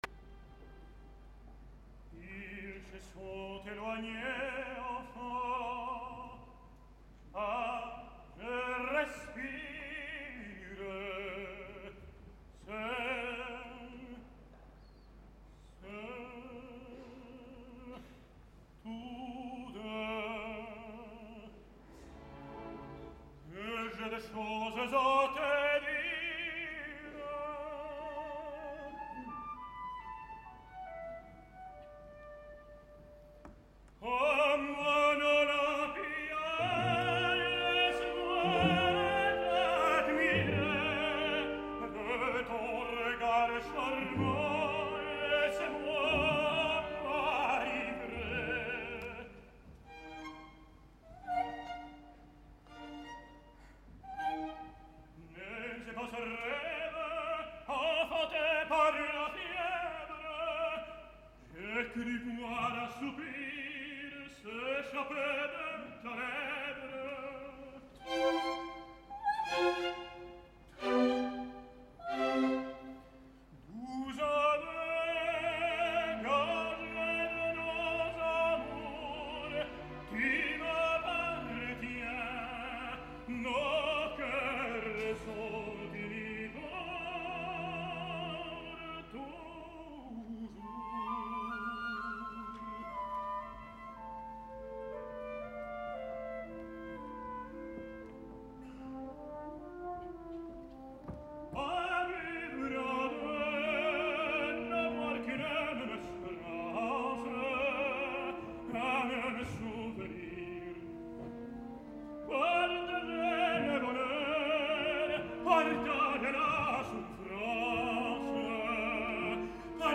En el primer acte, el de la nina Olympia, Hoffmann canta la preciosa ària “Ah! vivre deux, n’avoir qu’une même espérance”. Villazón tan sols canta, i amb extrema precaució, la primera estrofa, evitant els paranys del final escrit i dissimulant amb un llarg calderó al final de la primera.